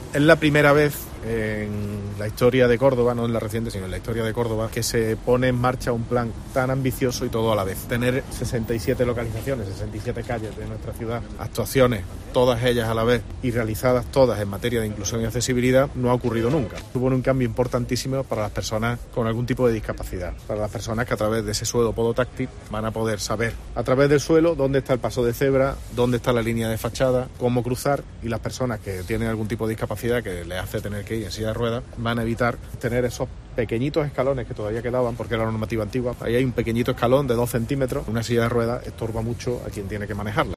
En declaraciones a los periodistas, en la calle Úbeda, confluencia con Plaza del Mediodía, el regidor ha explicado que "se enmarcan dentro del compromiso como gobierno local", de cara a "construir una ciudad más accesible, que en este caso consiste en actuaciones muy concretas", ha destacado.